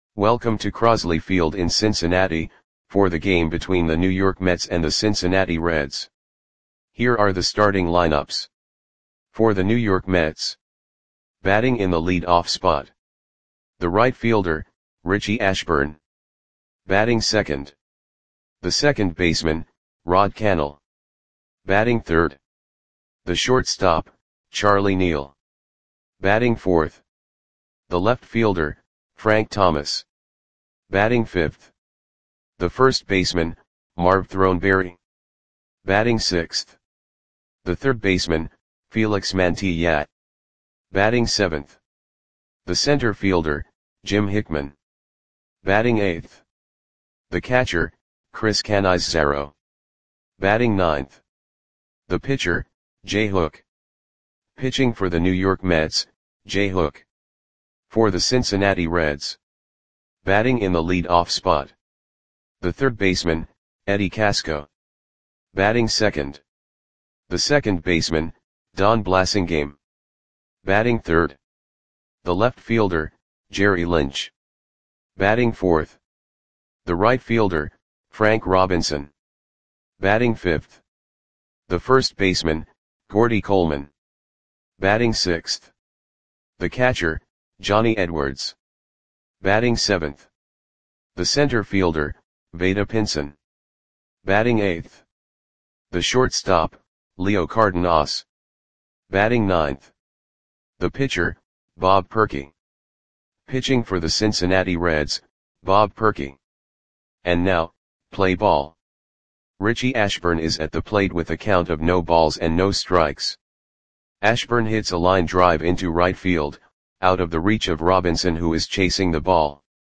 Audio Play-by-Play for Cincinnati Reds on August 11, 1962
Click the button below to listen to the audio play-by-play.